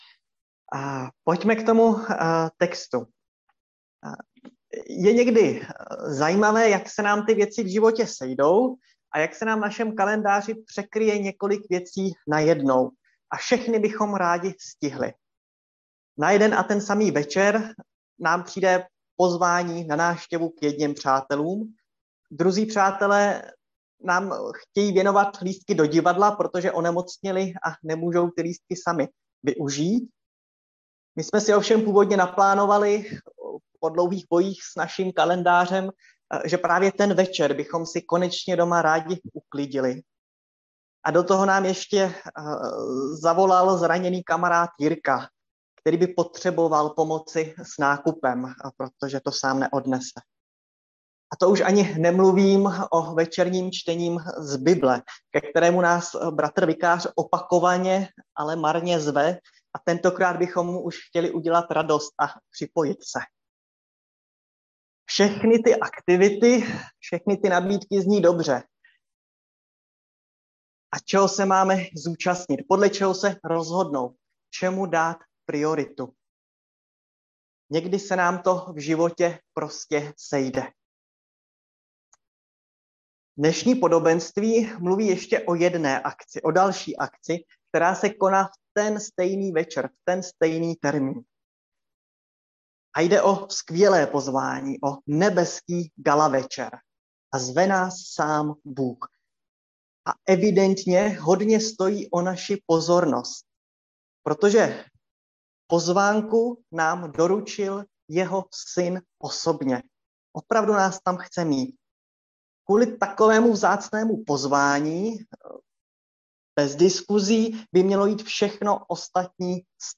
Nedělní kázání 21.3.2021